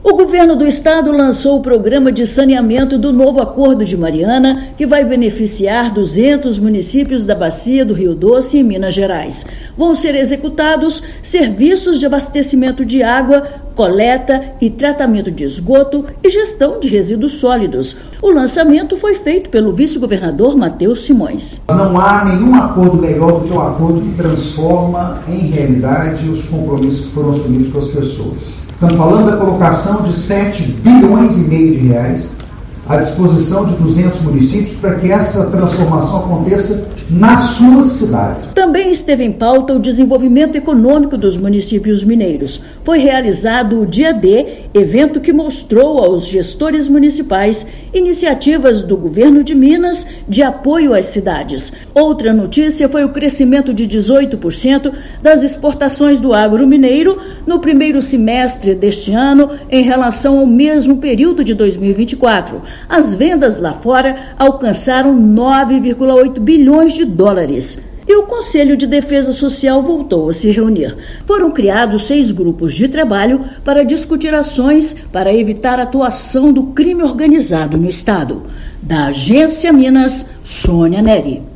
Programa de Saneamento do Novo Acordo de Mariana vai beneficiar 200 municípios da bacia do rio Doce em Minas Gerais. Valor previsto para ser executado no estado é de R$ 7,54 bilhões. Ouça matéria de rádio.